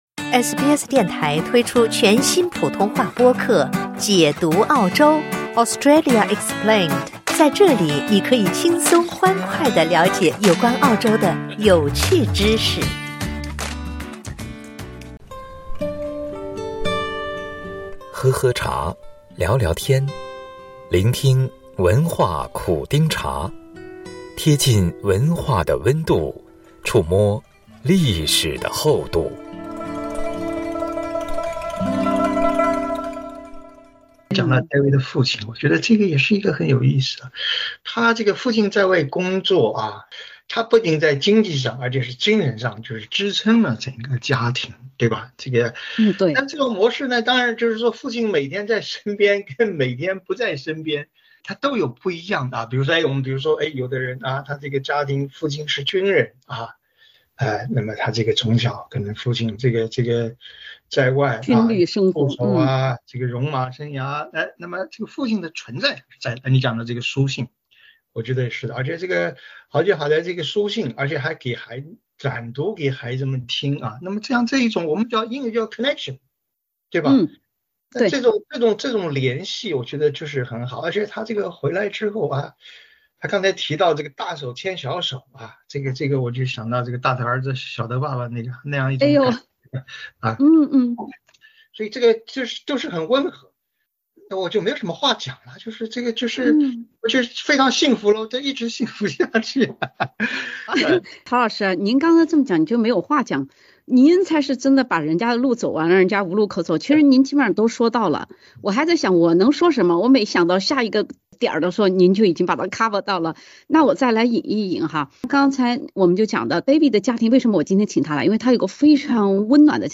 SBS 普通话电台
嘉宾们期望，能和大家一起在笑谈中学习，在讨论中成长。